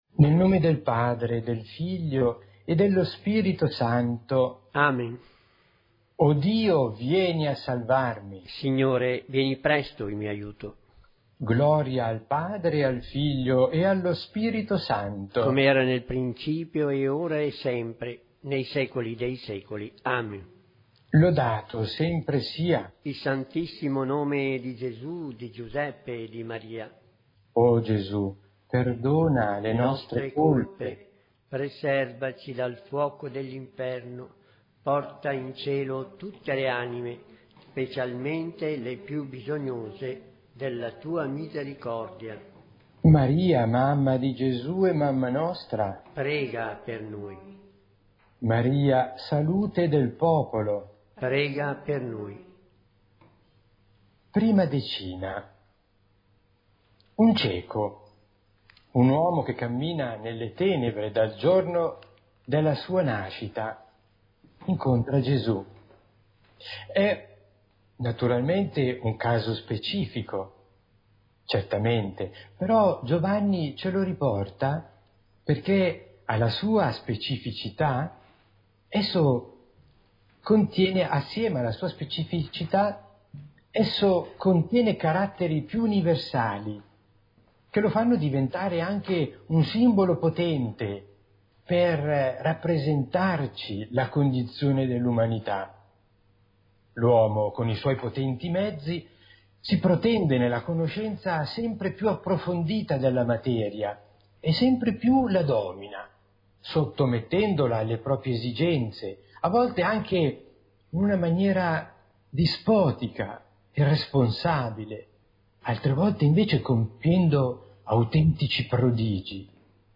Santo Rosario